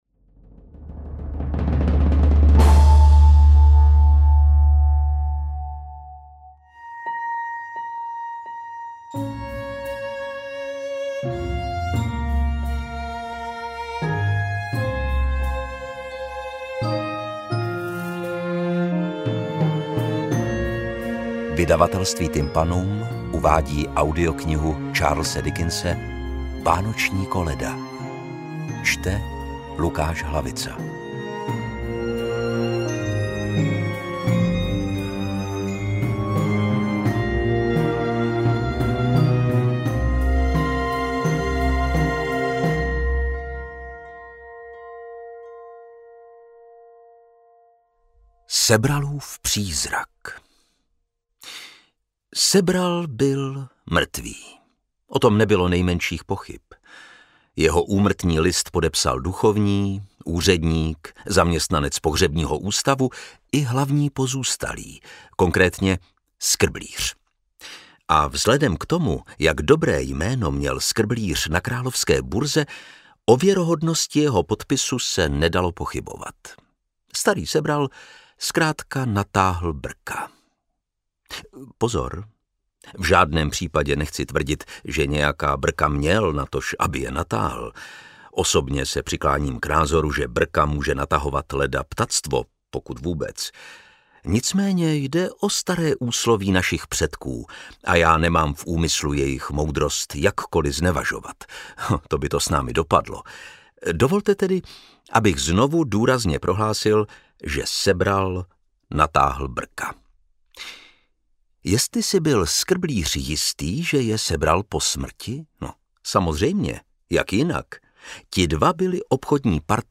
Interpret:  Lukáš Hlavica
AudioKniha ke stažení, 12 x mp3, délka 3 hod. 54 min., velikost 215,1 MB, česky